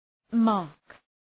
Προφορά
{mɑ:rk}